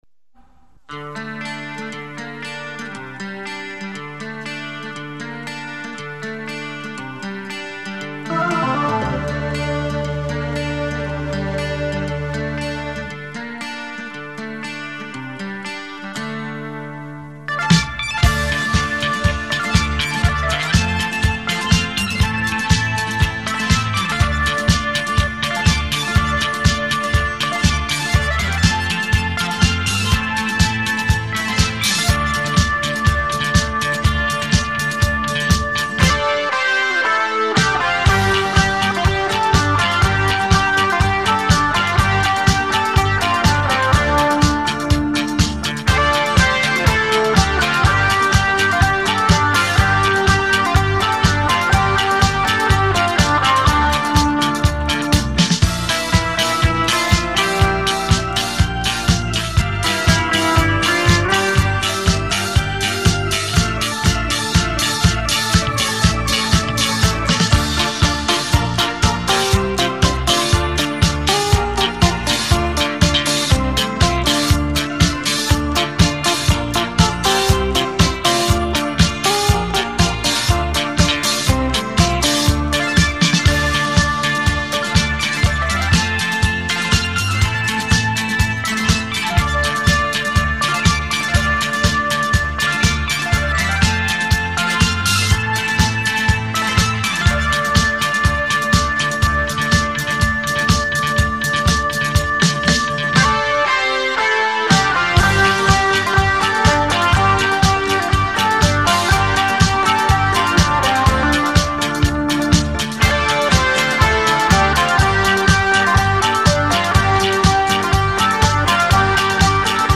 音质一般